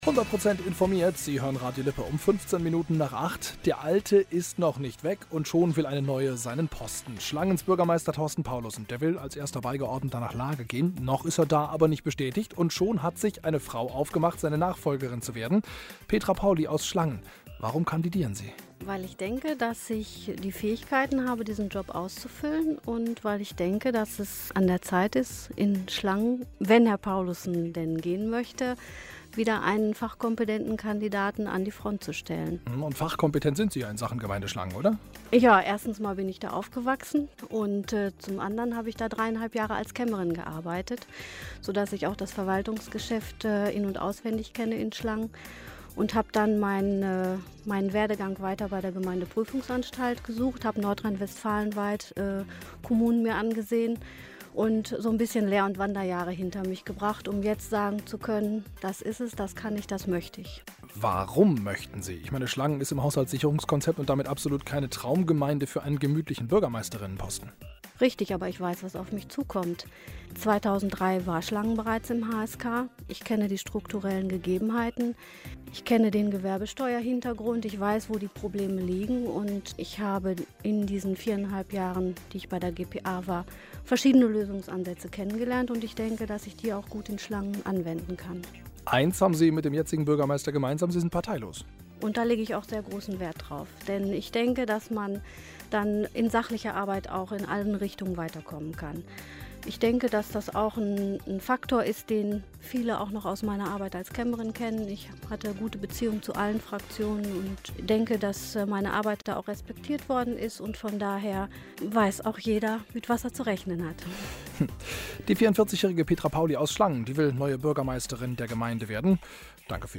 durch einen Klick auf das "Radio Lippe-Logo" können Sie Redebeiträge hören
Kandidaten-Talk zur Bürgermeister-Wahl Radio Lippe bei der Podiumsdiskussion in Schlangen